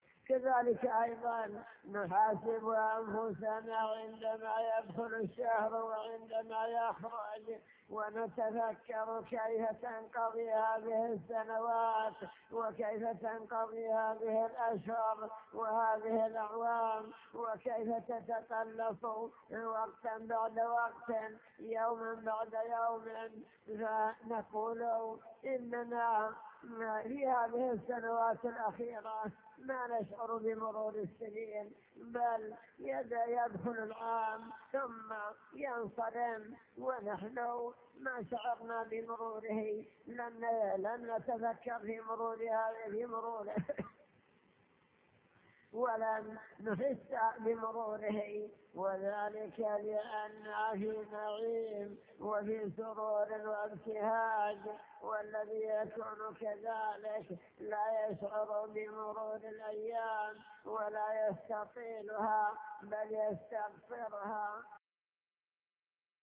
المكتبة الصوتية  تسجيلات - محاضرات ودروس  محاضرة بعنوان المسلم بين عام مضى وعام حل